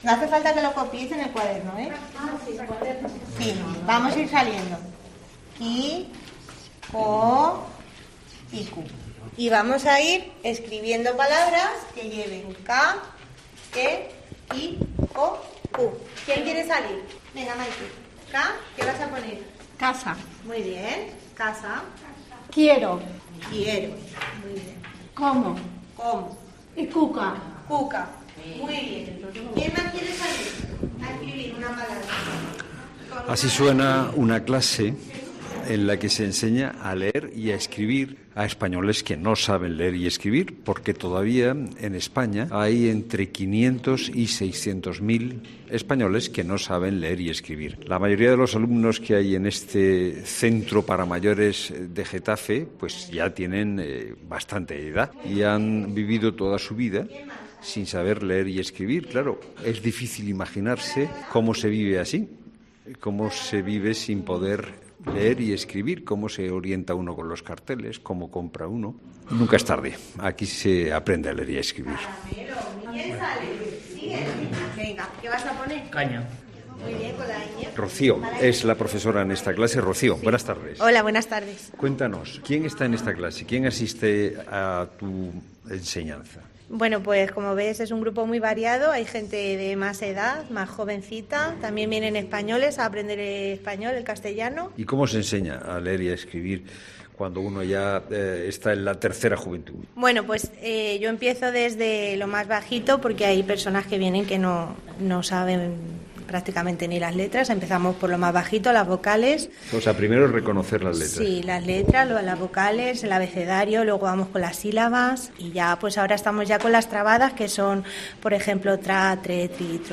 Fernando de Haro ha visitado una clase para adultos en la Casa de la Cultura de Getafe, en Madrid, donde aprenden desde cero a leer y escribir